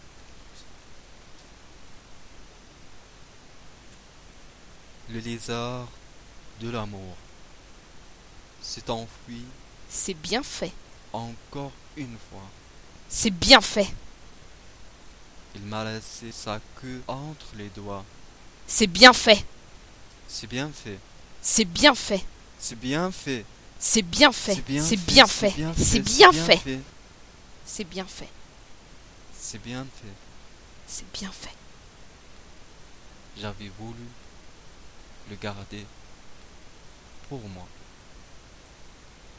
Proposé par l'atelier théâtre adulte du Centre Nelson Mandela